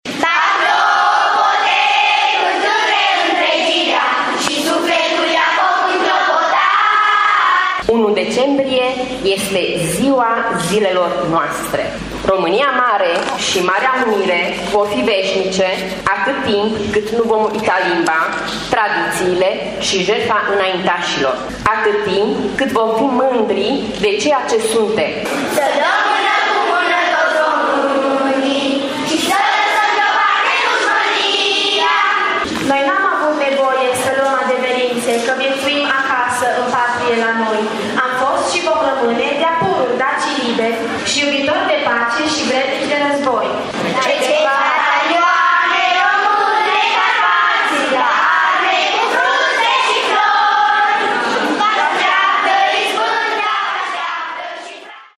Îmbrăcați cu toții în costume naționale și cu tricolor la brâu, copiii au recitat poezii și i-au cântat României.